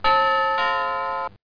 DoorGong1.mp3